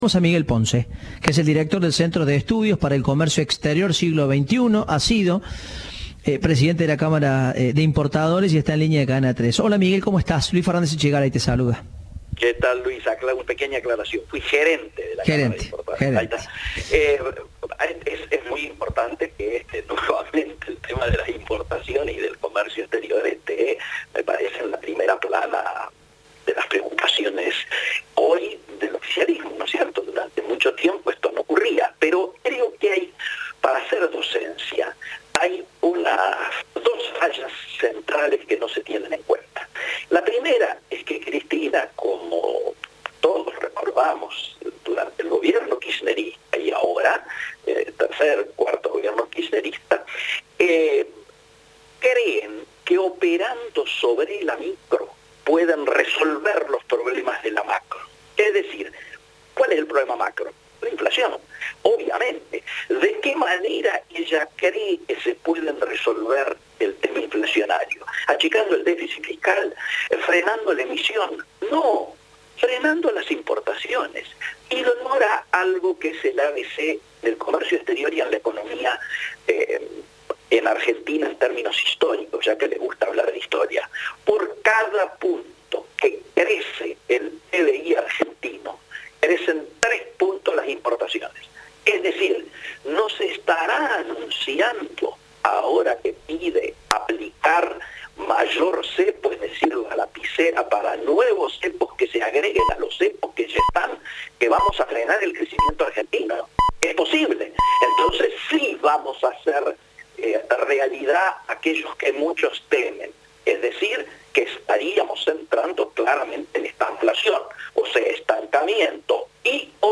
Entrevista de "Informados, al regreso"